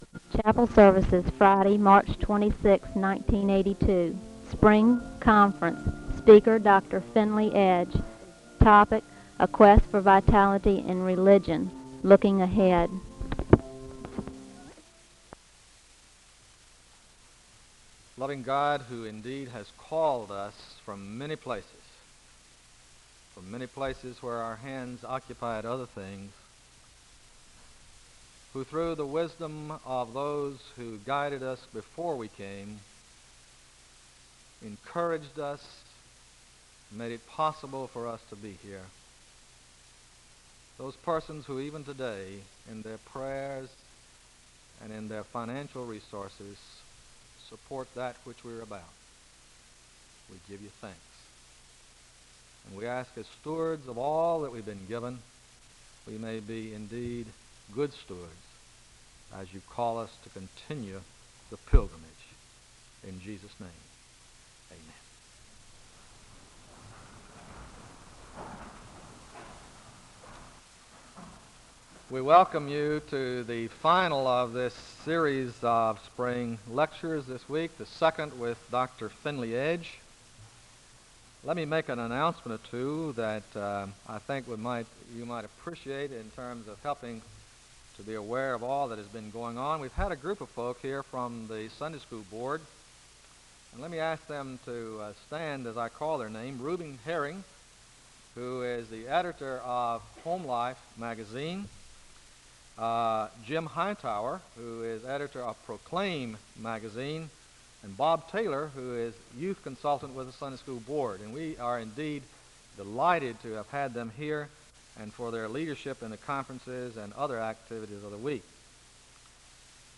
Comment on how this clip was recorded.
Audio quality is poor.